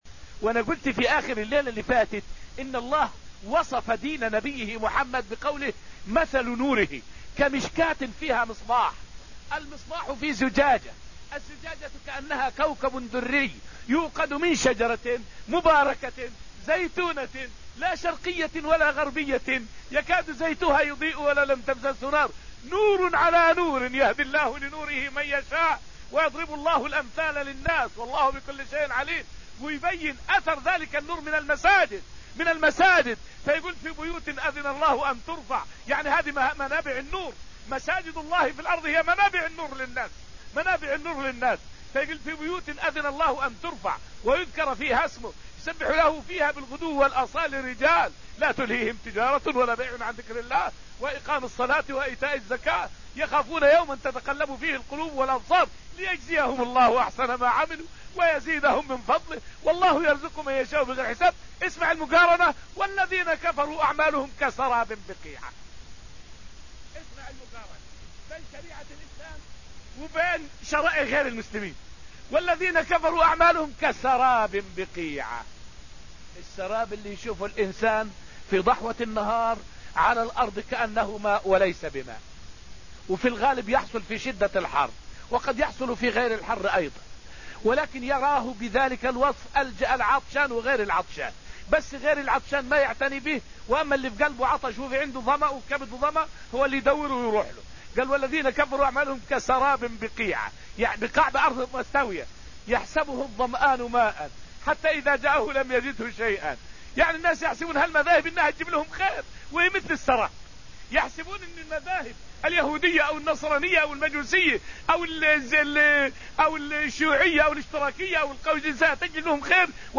فائدة من الدرس التاسع من دروس تفسير سورة الحديد والتي ألقيت في المسجد النبوي الشريف حول معنى قوله تعالى: {والذين كفروا أعمالهم كسراب بقيعة}.